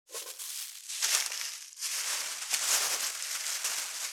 619ゴミ袋,スーパーの袋,袋,買い出しの音,ゴミ出しの音,袋を運ぶ音,
効果音